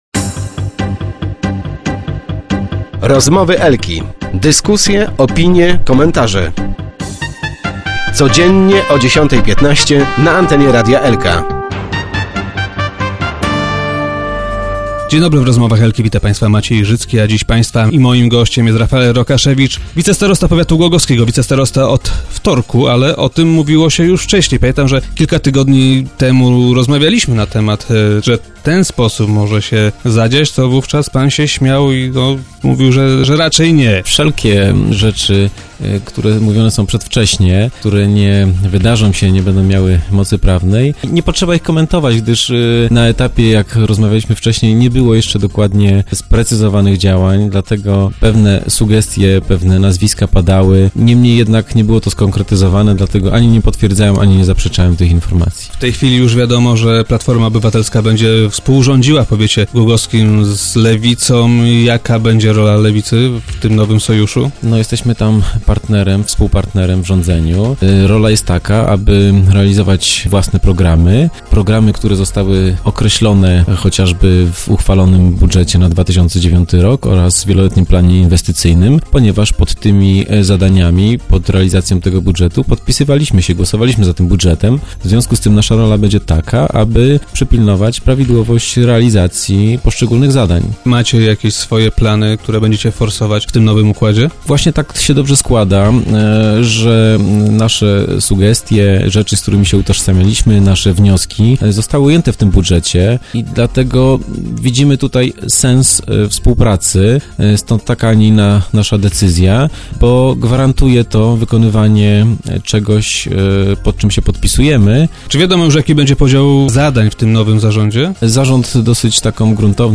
- Chciałbym zajmować się sprawami związanym i gospodarką - powiedział wicestarosta Rafael Rokaszewicz, który dziś był gościem Rozmów Elki.